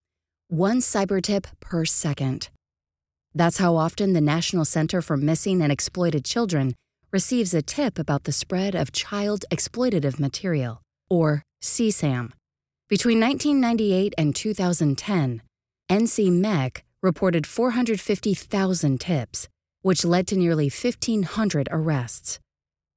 Female
Yng Adult (18-29), Adult (30-50)
Documentary Spot
Words that describe my voice are Genuine, Informative, Persuasive.
All our voice actors have professional broadcast quality recording studios.